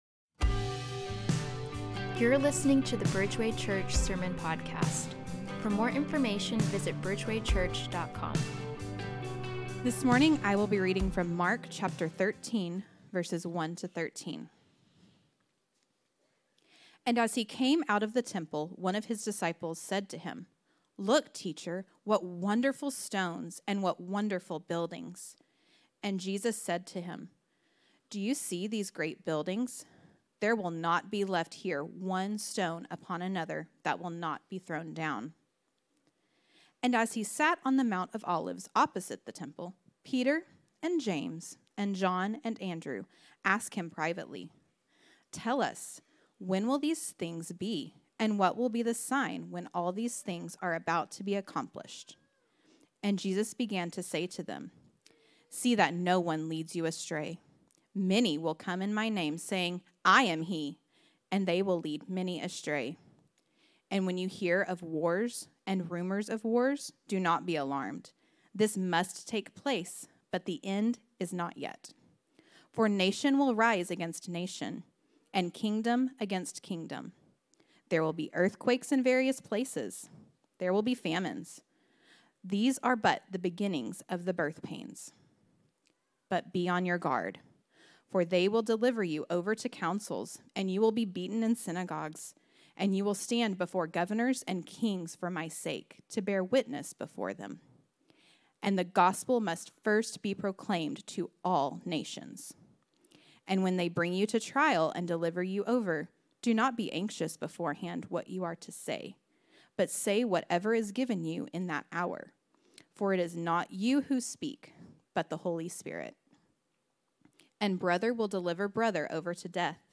march-2-2025-sermon-audio.m4a